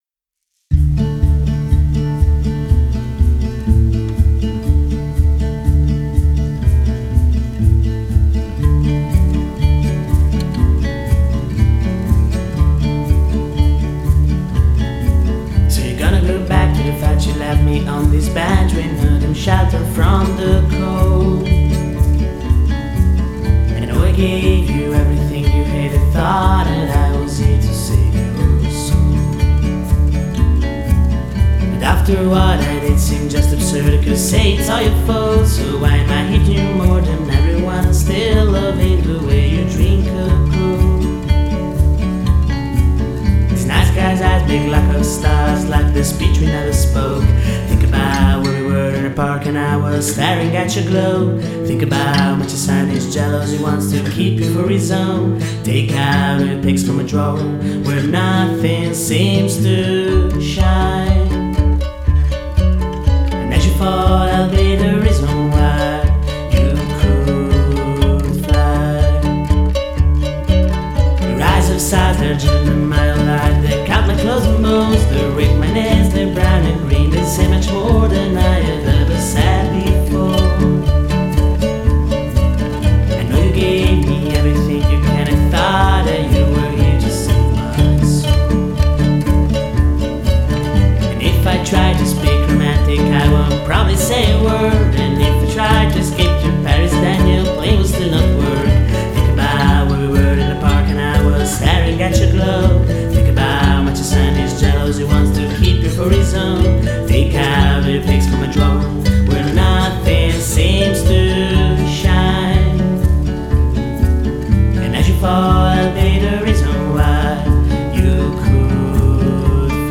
Luogo esecuzioneStop! Studio
GenereWorld Music / Folk